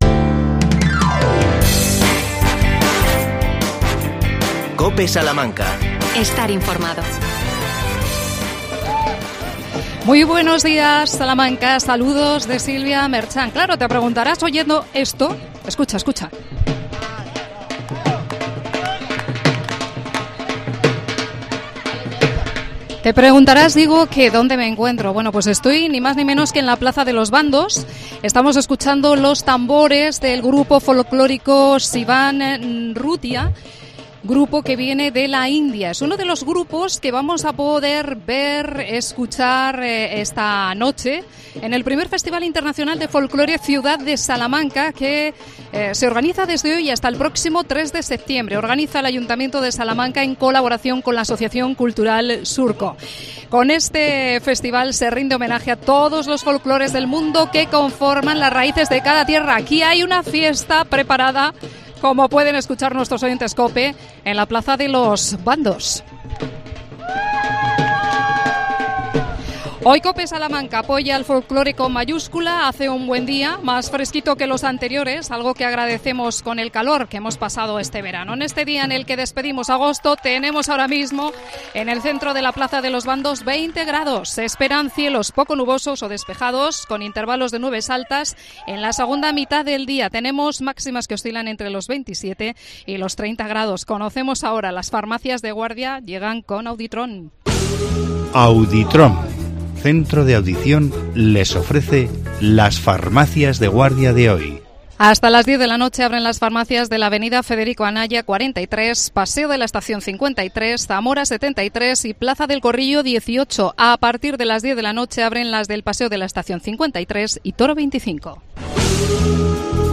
AUDIO: Arranca el I Festival Internacional de Folklore Ciudad de Salamanca. En directo, desde la Plaza de los Bandos